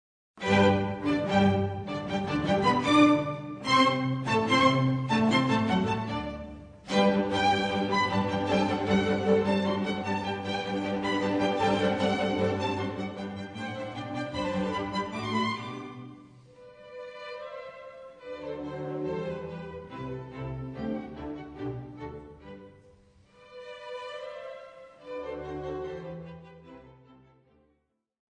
Osserviamo e ascoltiamo l'inizio della celebre Piccola serenata notturna di Wolfgang Amadeus Mozart (1756-1791): Un perfetto esempio di simmetria, proporzione, equilibrio! mozart_eine_kleine_nachtmusik.mp3